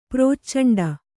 ♪ prōccaṇḍa